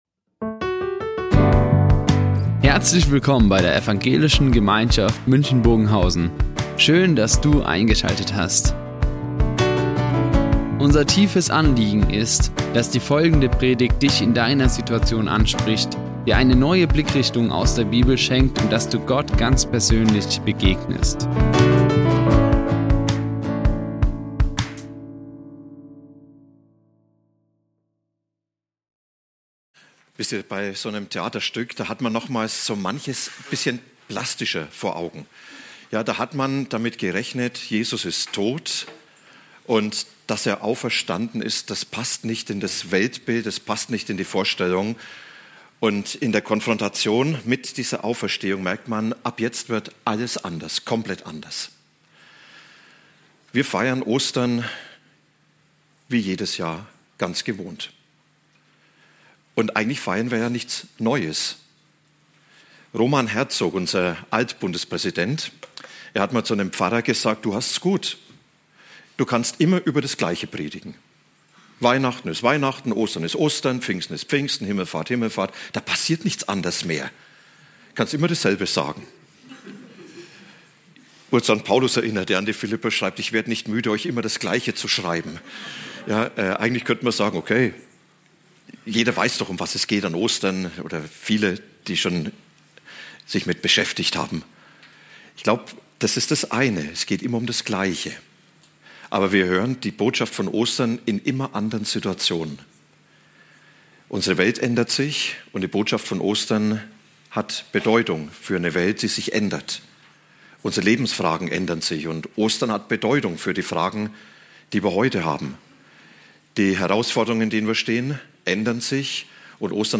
Wendepunkt Auferstehung | Predigt Johannes 20, 11-18 ~ Ev.
11-18 zum Thema "Wendepunkt Auferstehung" Die Aufzeichnung erfolgte im Rahmen eines Livestreams.